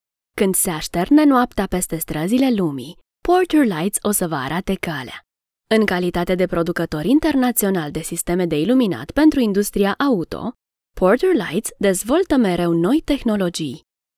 Seguro, Amable, Cálida, Suave, Empresarial
Corporativo